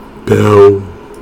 Cries
WEEPINBELL.mp3